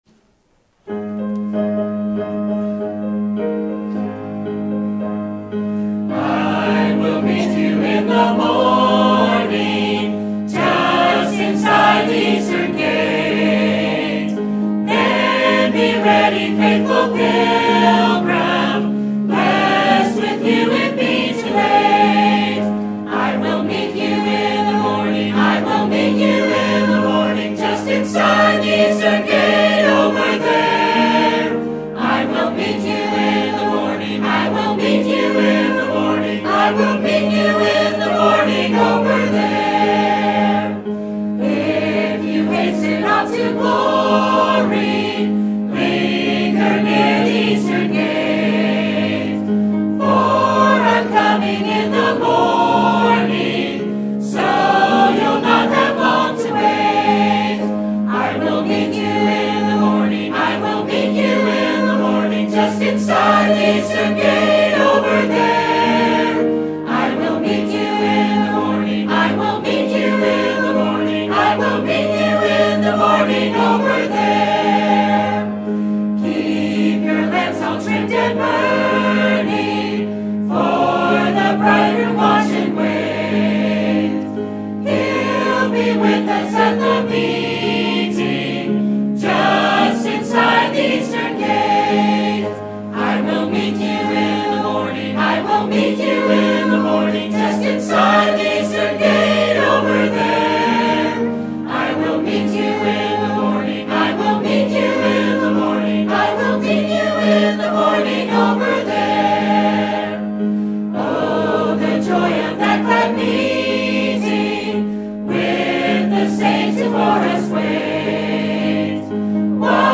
Sermon Topic: General Sermon Type: Service Sermon Audio: Sermon download: Download (27.07 MB) Sermon Tags: Romans Missions Salvation Joy